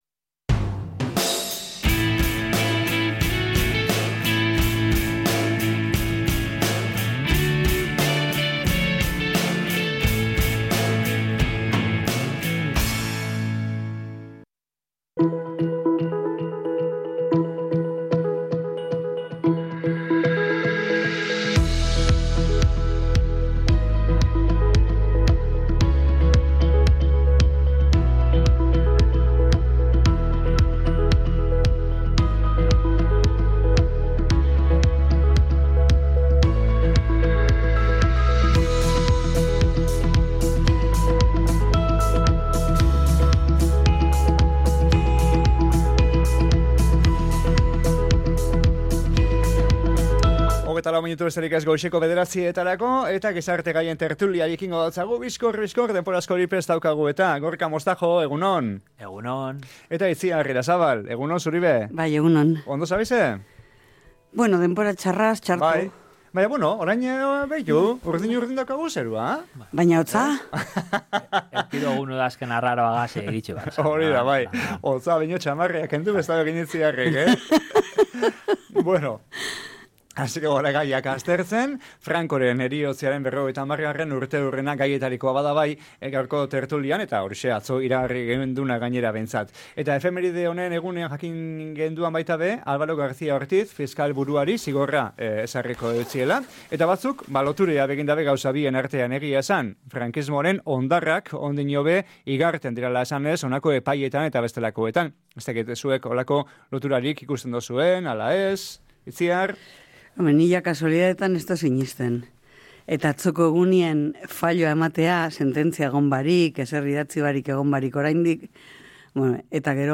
GIZARTE-GAIEN-TERTULIA--1.mp3